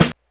snare05.wav